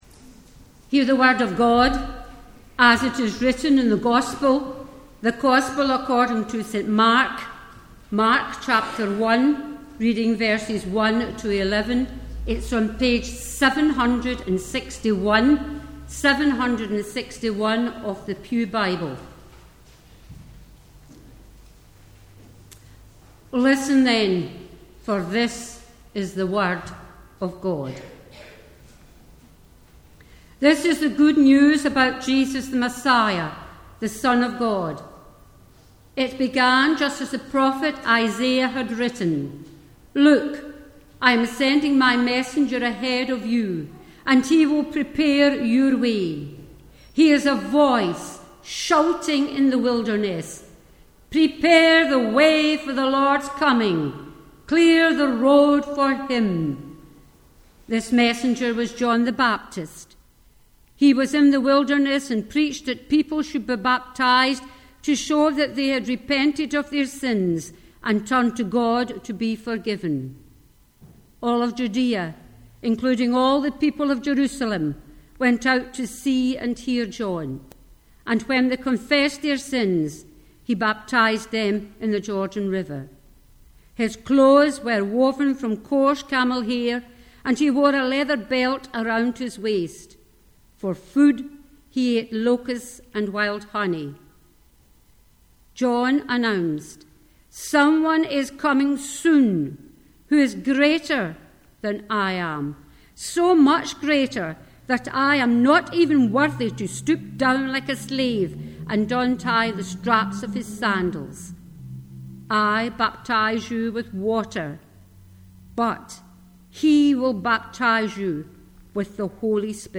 24th June Sermon